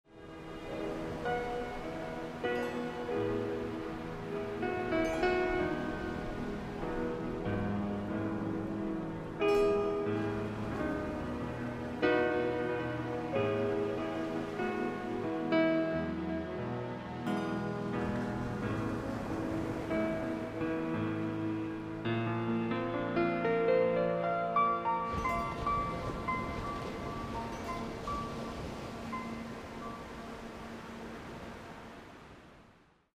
stimulating (neo-)classical music
Sound and music pure